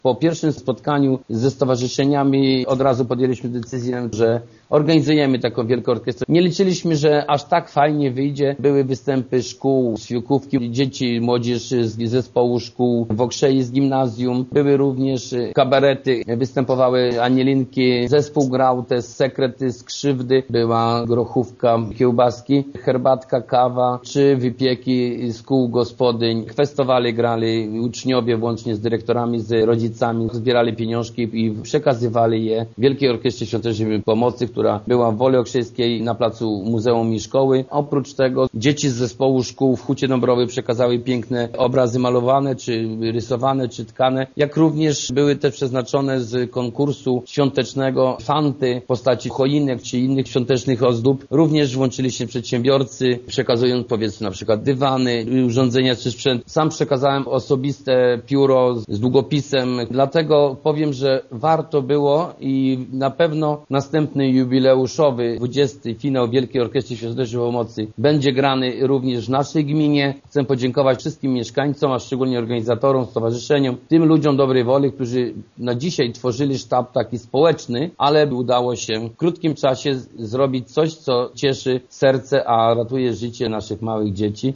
Wójt Jerzy Kędra przyznaje, że decyzja była spontaniczna, ale warto było ją podjąć. Zapowiada już także, że za rok gmina przyłączy się też do kolejnego, 20 finału orkiestry: